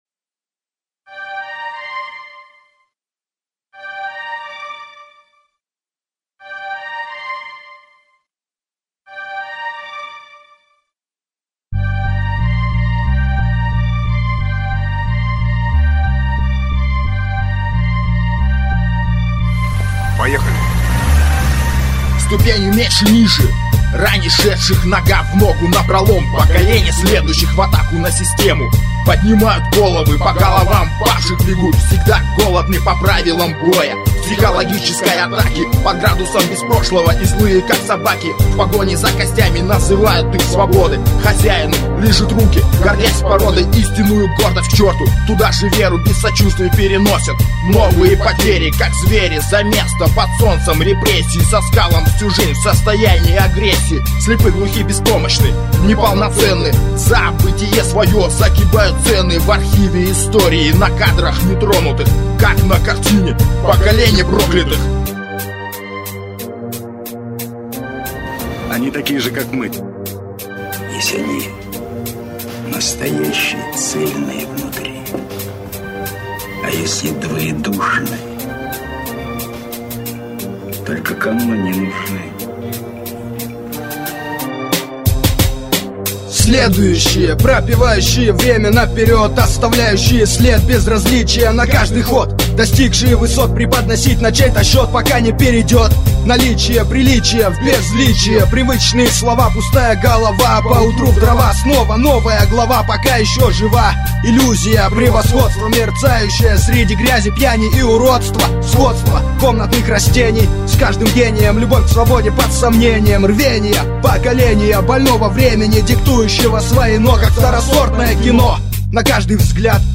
undeground rap